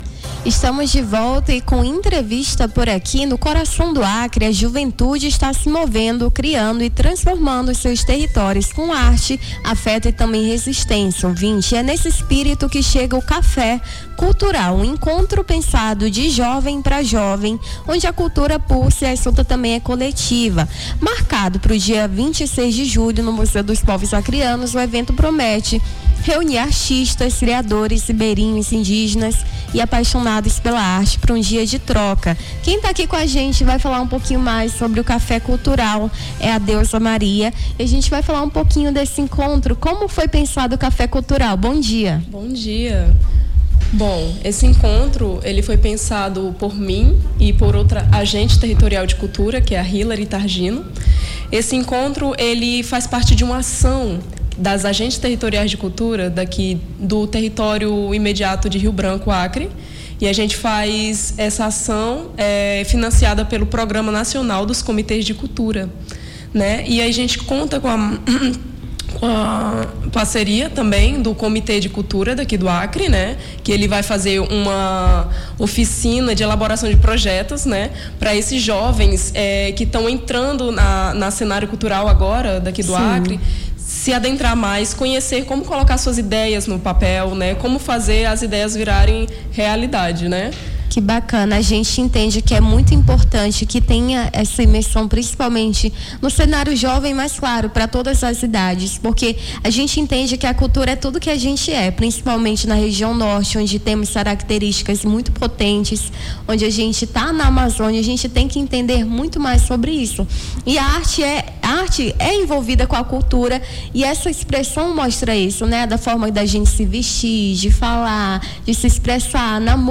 Nome do Artista - CENSURA - ENTR CAFÉ CULTURAL - 26.07.2025.mp3